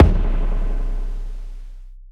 Spring_live_revKick.wav